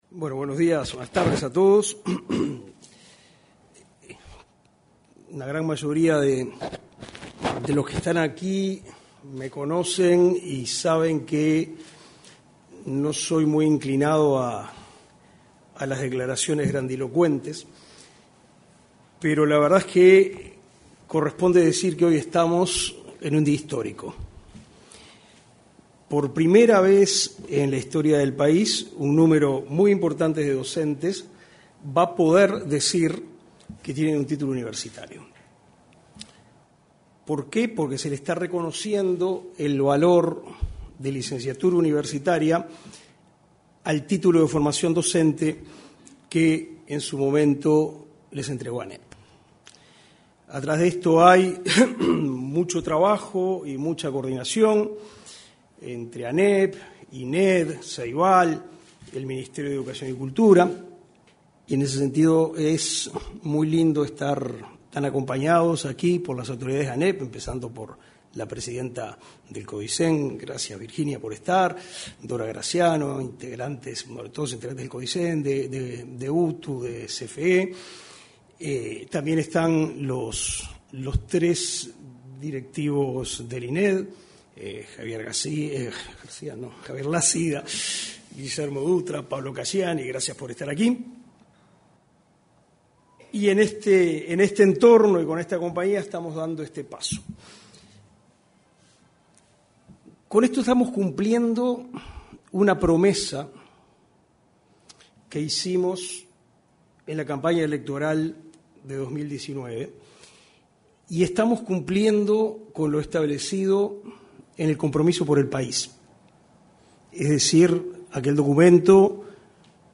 Presentación de los Resultados de la Primera Edición de la Prueba Docente Acreditado 03/09/2024 Compartir Facebook X Copiar enlace WhatsApp LinkedIn Este martes 3 en el salón de actos de la Torre Ejecutiva se efectuó la presentación de los resultados de la primera edición de la prueba Docente Acreditado. En la oportunidad, se expresaron el ministro de Educación y Cultura, Pablo da Silveira; el presidente del Instituto Nacional de Evaluación Educativa (Ineed), Javier Lasida; y el director Nacional de Educación, Gonzalo Baroni.